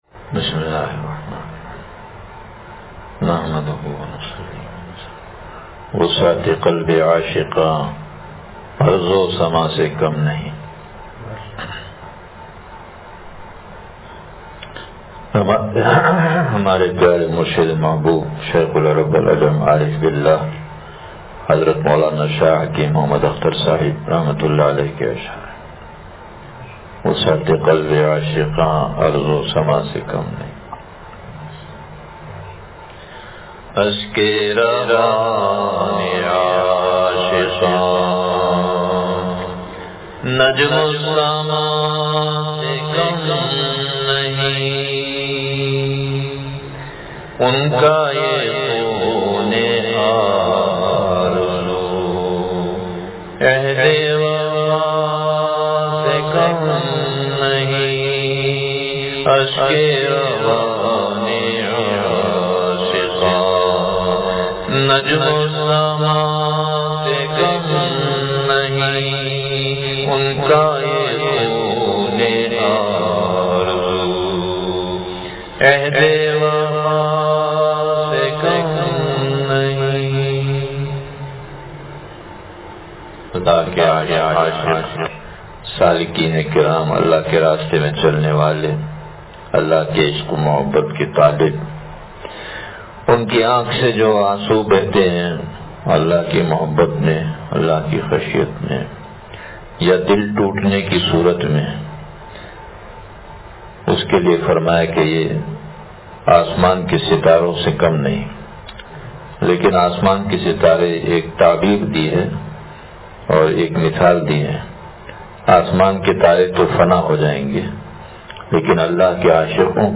وسعتِ قلب عاشقاں ارض و سماء سے کم نہیں – شیخ العرب والعجم عارف باللہ مجدد زمانہ حضرت والا رحمتہ اللہ علیہ کا وعظ دعا سے پڑھا